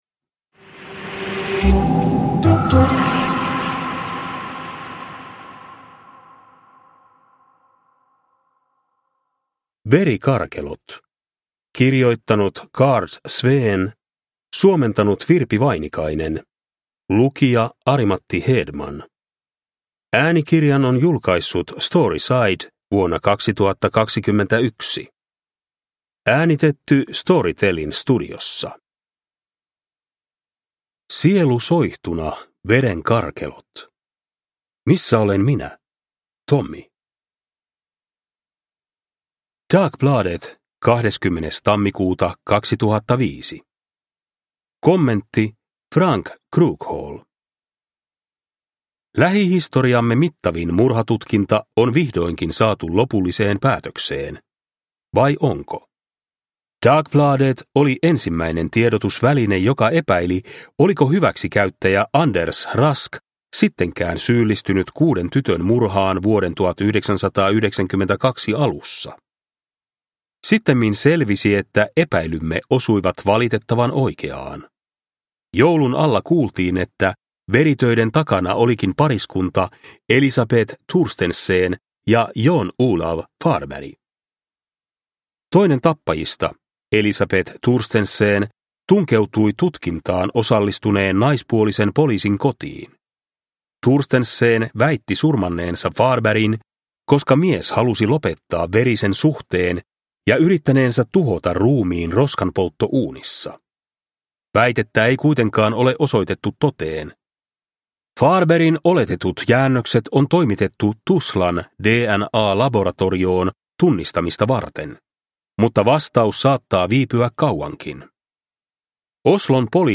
Verikarkelot – Ljudbok – Laddas ner